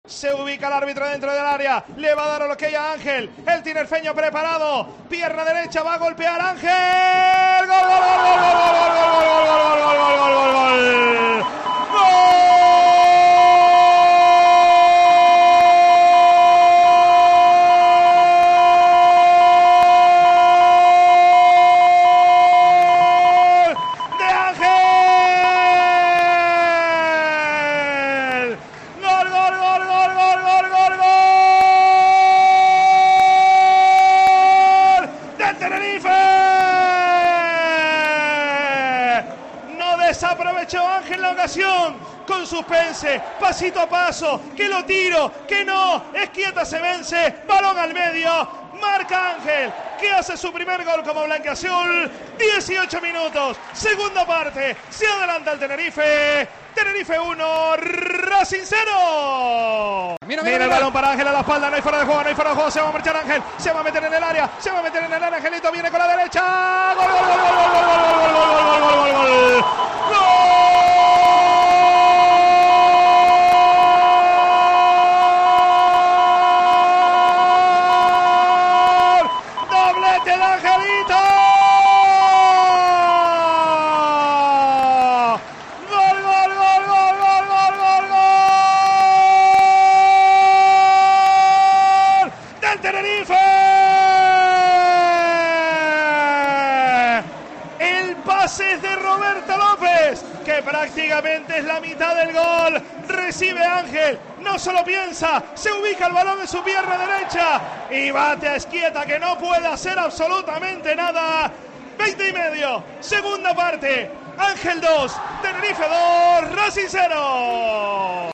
Así sonaron los goles de la victoria del CD Tenerife